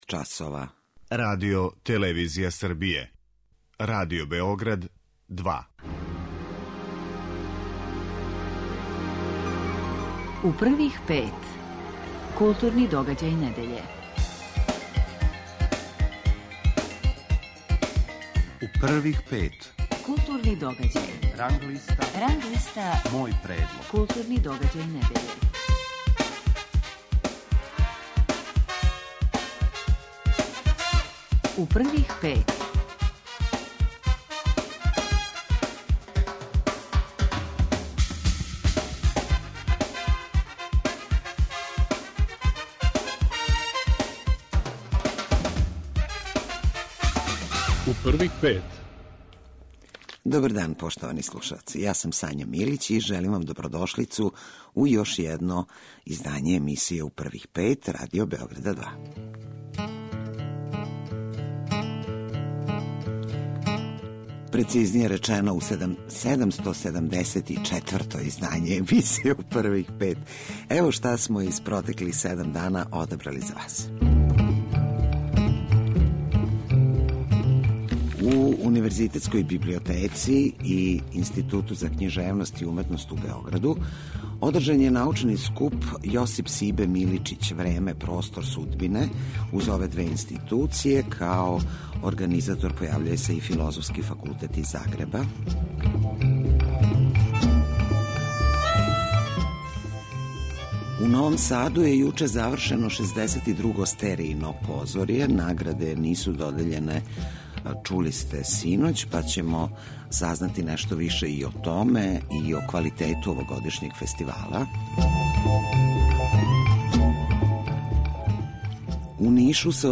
Гост емисије биће Владимир Пиштало, писац. Разговараћемо о његовој новој књизи 'Сунце овог дана. Писмо Андрићу'.
Новинари и критичари Радио Београда 2 издвајају најбоље, најважније културне догађаје у свим уметностима у протеклих седам дана и коментаришу свој избор.